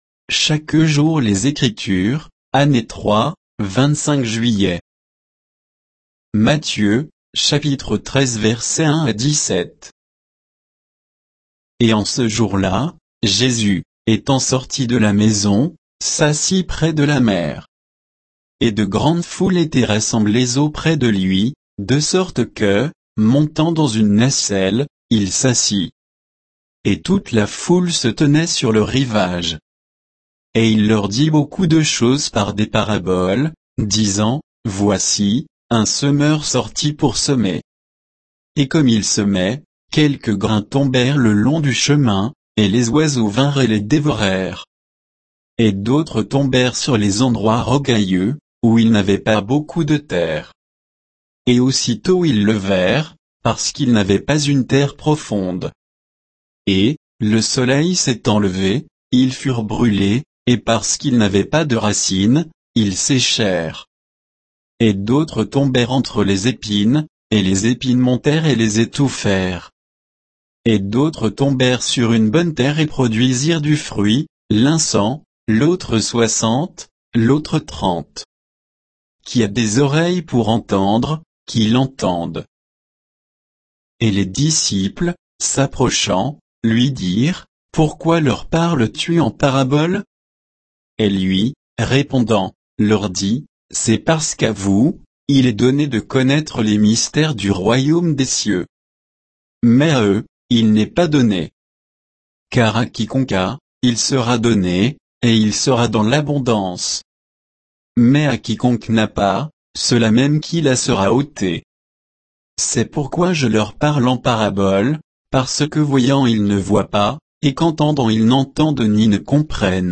Méditation quoditienne de Chaque jour les Écritures sur Matthieu 13, 1 à 17